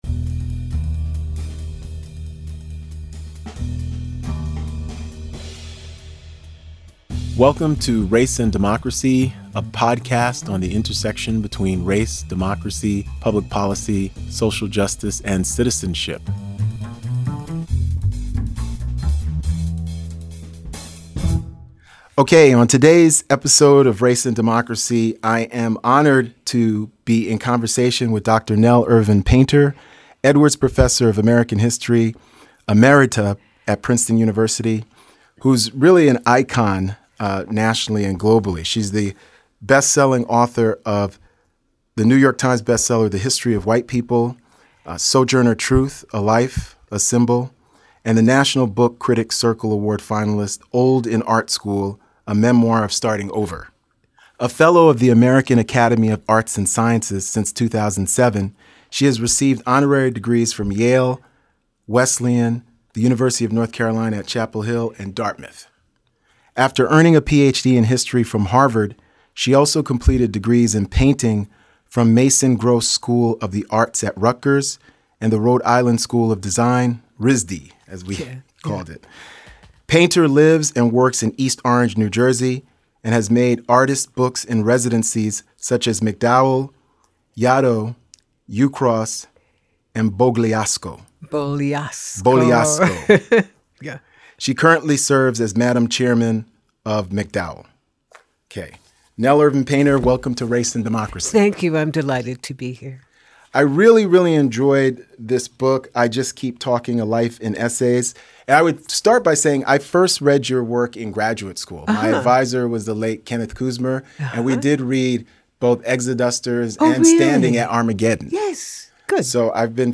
Ep. 89 — A Conversation with Dr. Nell Irvin Painter on Black History, Visual Art, and a Well Lived Life